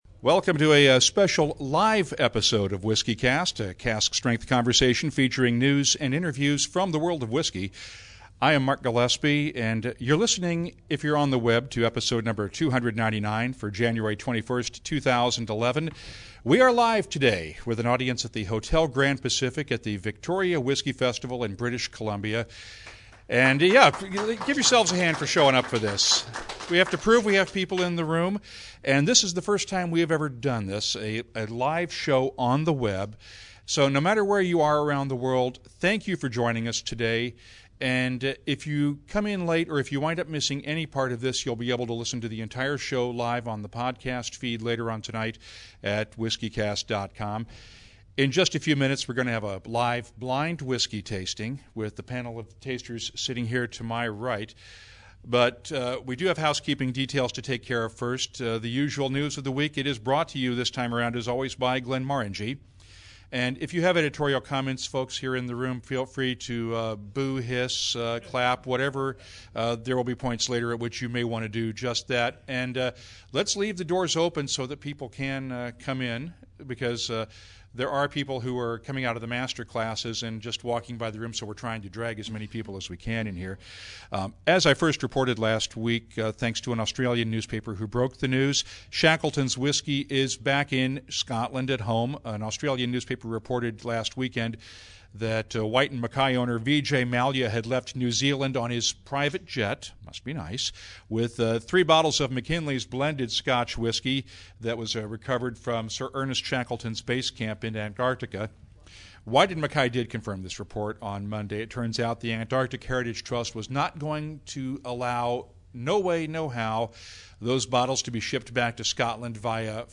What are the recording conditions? This is the first live webcast of WhiskyCast, on location at the Victoria Whisky Festival in British Columbia.